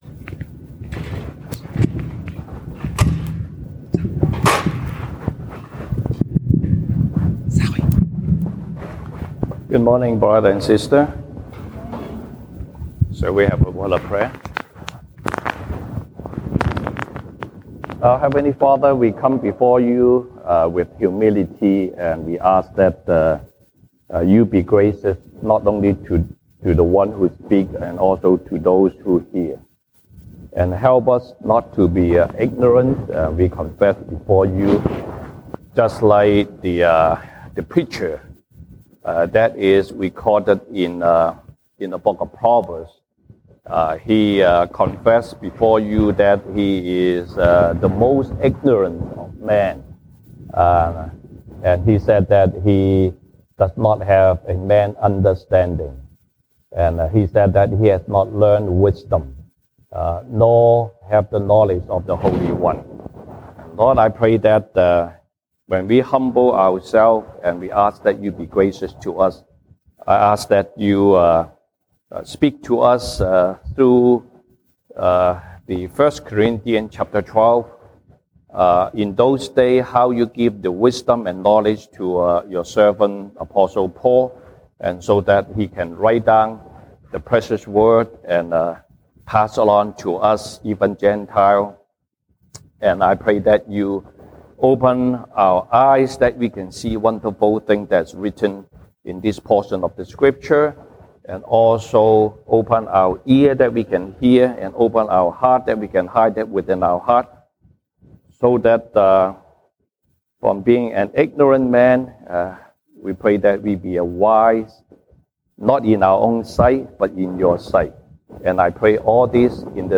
Passage: 歌林多前書 1 Corinthians 12:1-13 Service Type: 西堂證道 (英語) Sunday Service English Topics: The work of the Holy Spirit